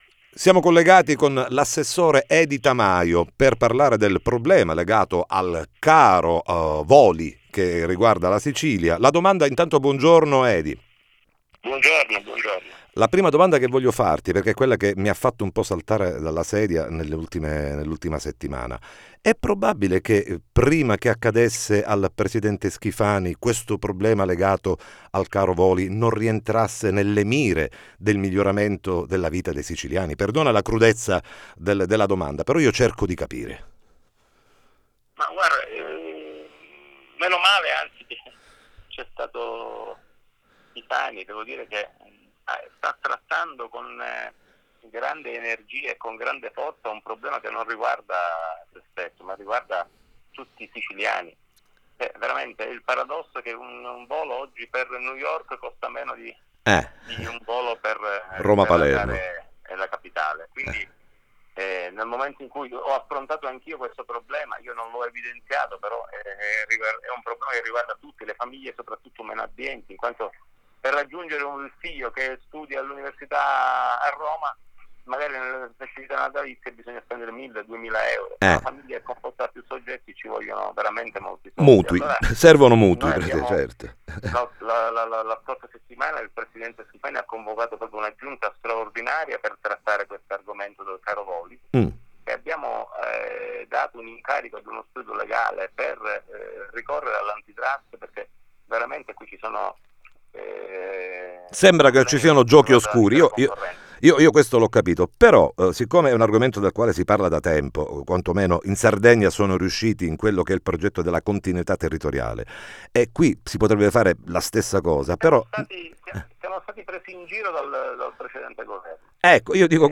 TM Intervista Edy Tamajo